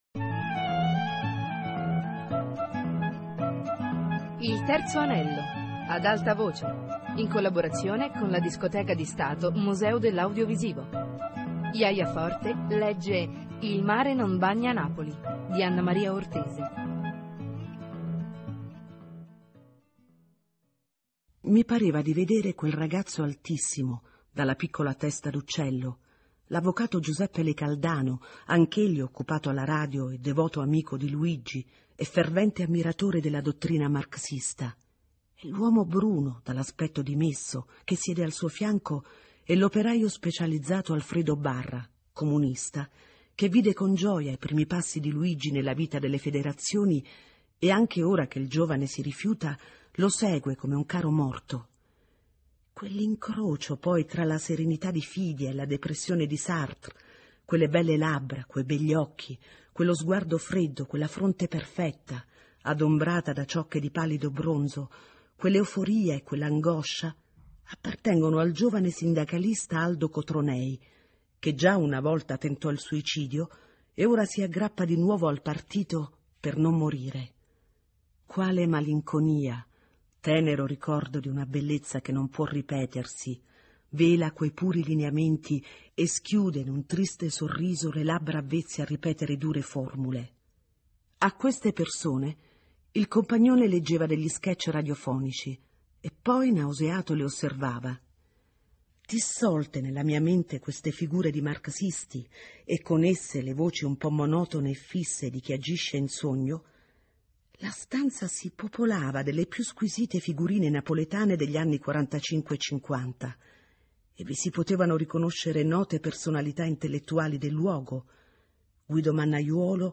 Lettura